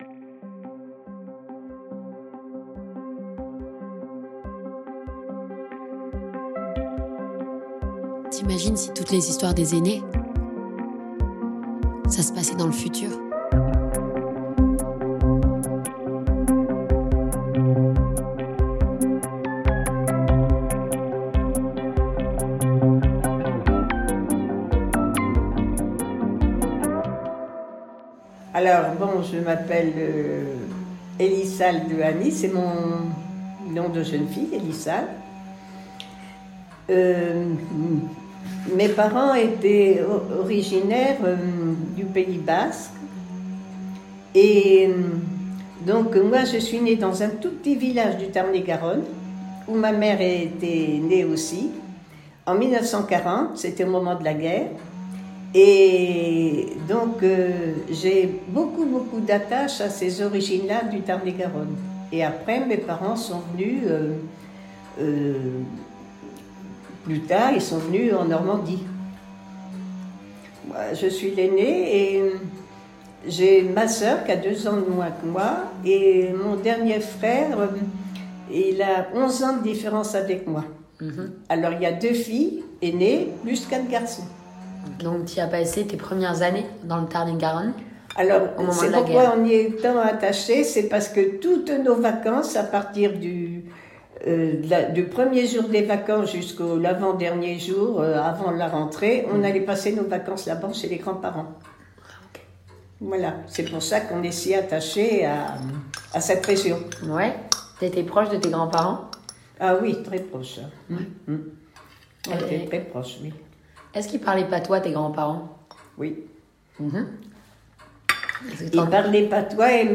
Partager Type Entretien Société vendredi 8 novembre 2024 Lire Pause Télécharger ÉPISODE 2 - Et que l'espoir demeure...